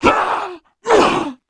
Index of /App/sound/monster/ice_snow_witch
dead_1.wav